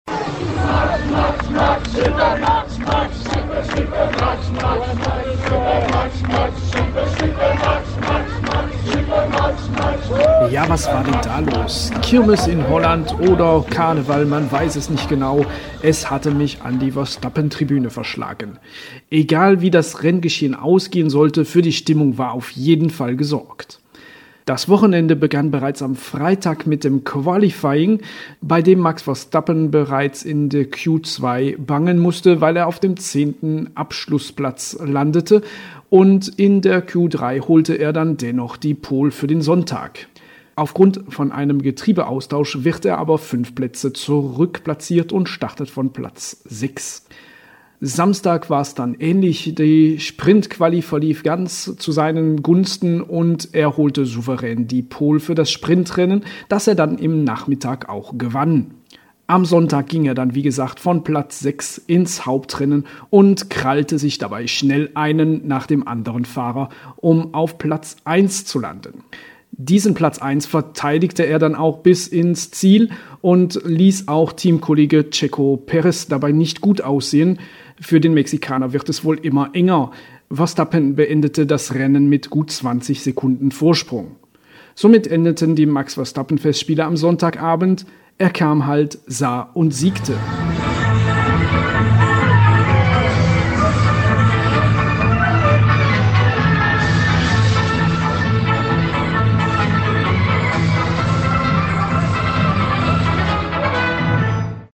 SPA_F1_2023.mp3